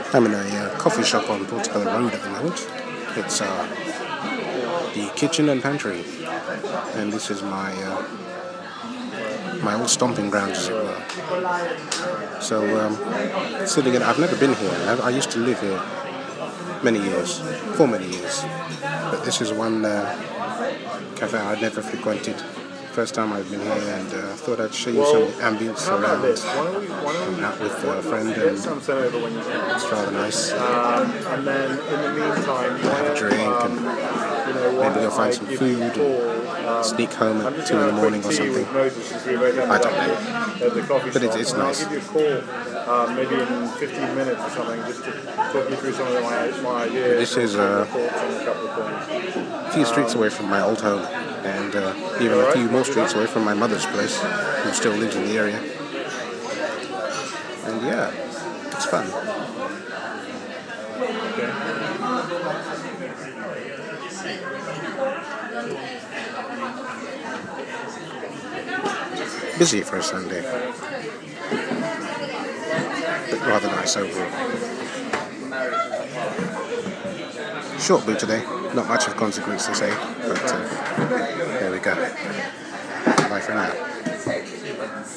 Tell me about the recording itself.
A boo from my old stomping grounds, Portobello Market hear where I used to live.